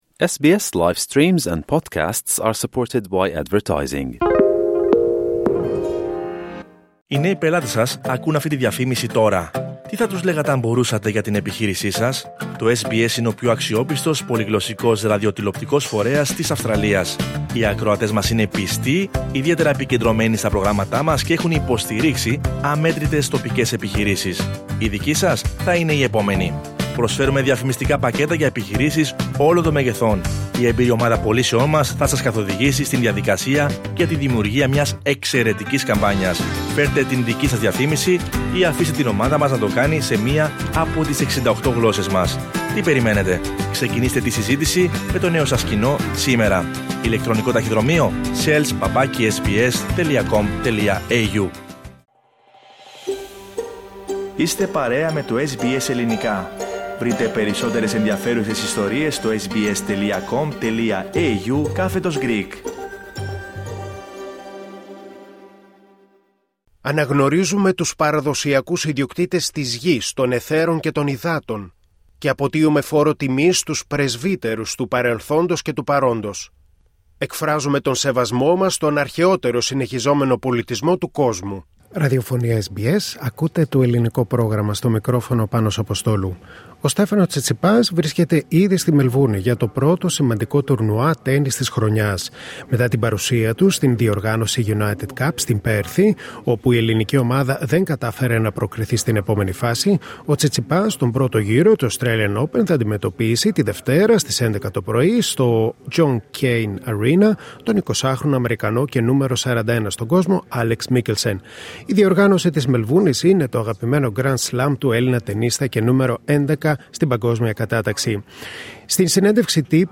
Ο κορυφαίος Έλληνας τεννίστας, Στέφανος Τσιτσιπάς βρίσκεται ήδη στη Μελβούρνη για το πρώτο σημαντικό τουρνουά τέννις της χρονιάς και απάντησε σε ερωτήσεις του SBS Greek